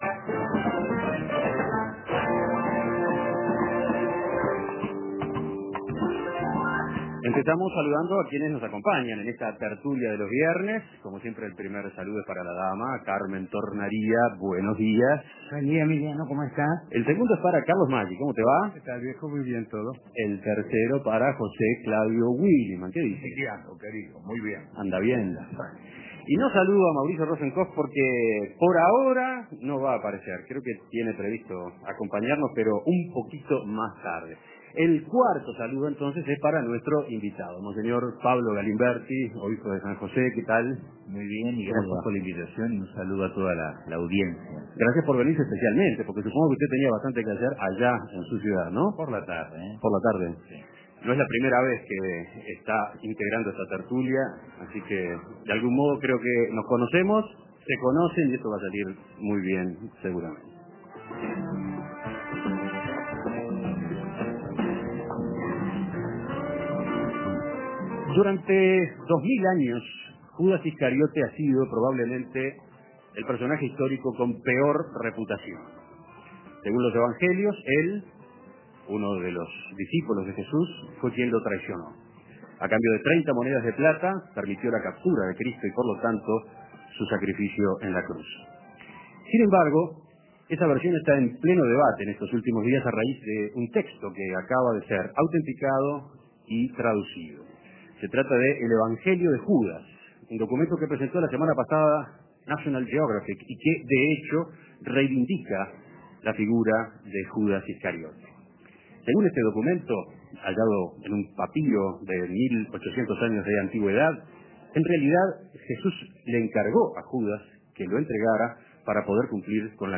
¿Cómo interpretar "El Evangelio de Judas"? Los contertulios de los viernes integran al debate a monseñor Pablo Galimbertti. El "clásico" de los viernes volvió al Oro del Rhin.